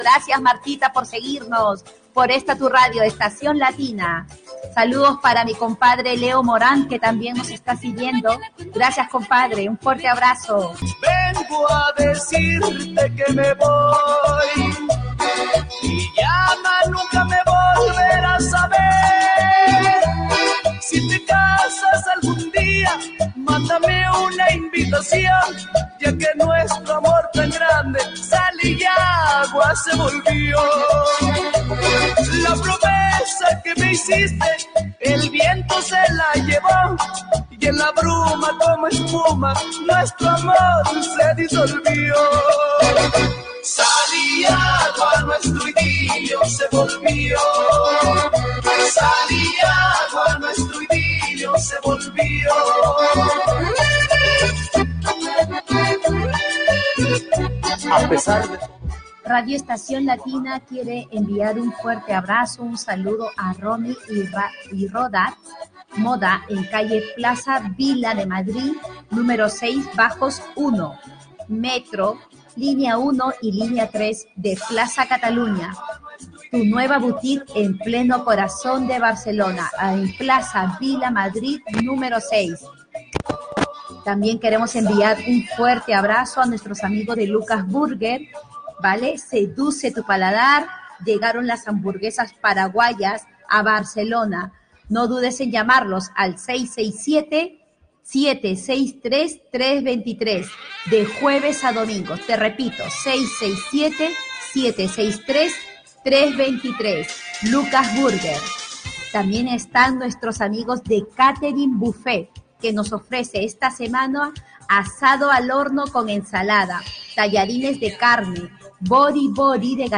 Salutacions amb identificació de la ràdio, publicitat
Entreteniment